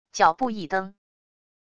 脚步一蹬wav音频